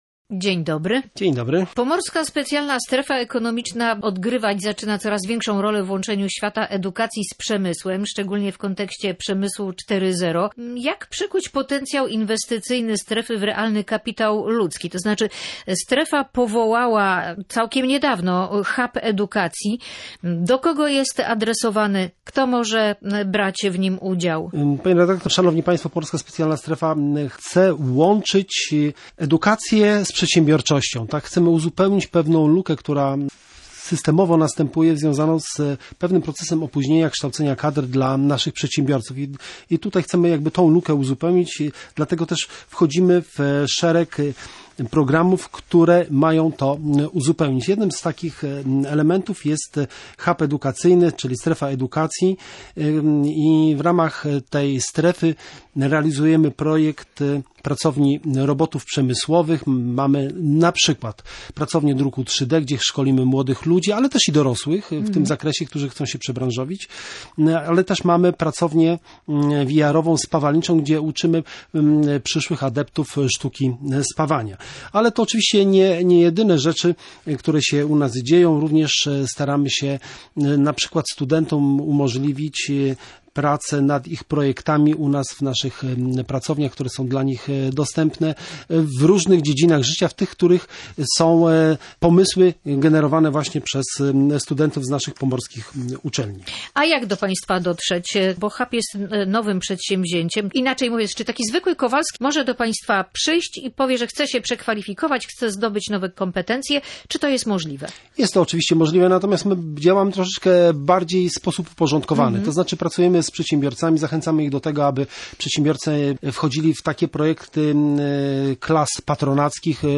Wywiad_gospodarczy_edukacja_i_PSSE.mp3